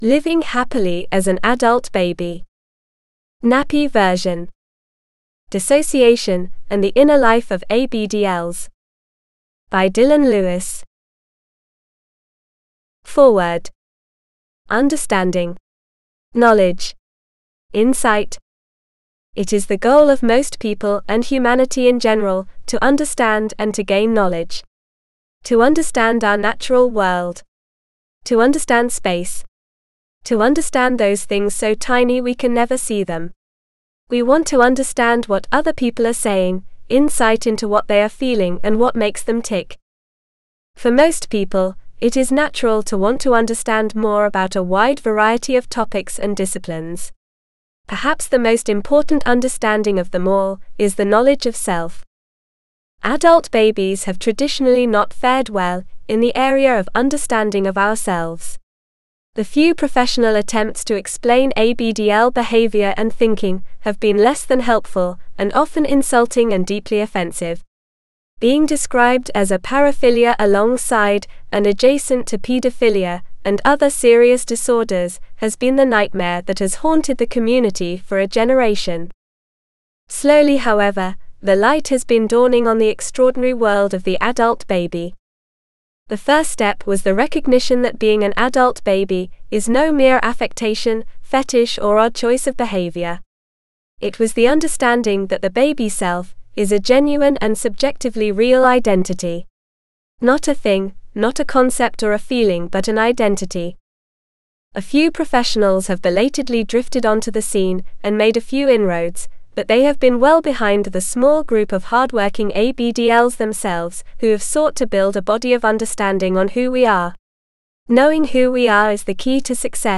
Living Happily as an AB (AUDIOBOOK- female): $US6.75